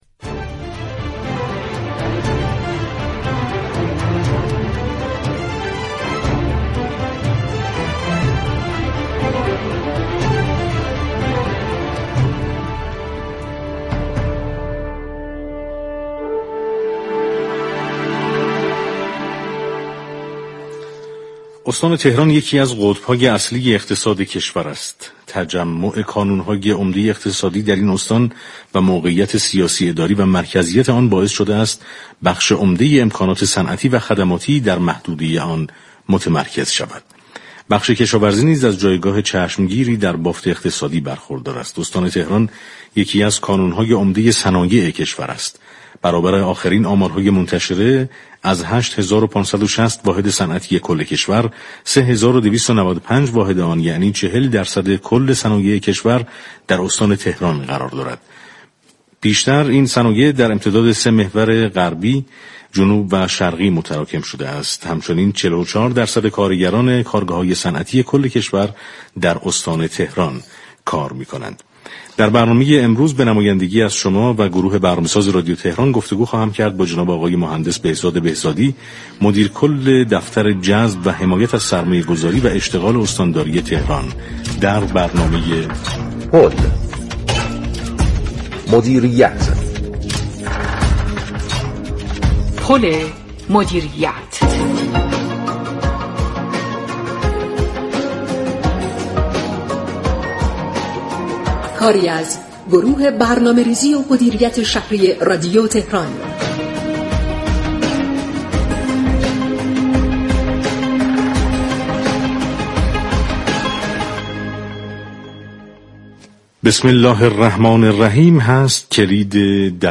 طی 8 ماه گذشته؛ 86 هزار فرصت شغلی در استان تهران ایجاد شده است بهزاد بهزادی مدیركل دفتر جذب و حمایت از سرمایه گذاری و اشتغال استانداری تهران با حضور در استودیو پخش زنده رادیو تهران در هفتمین روز از آذرماه با بیان اینكه استان تهران رتبه اول ایجاد اشتغال در كشور را به خود اختصاص داده گفت: بر اساس گزارش های سامانه رصد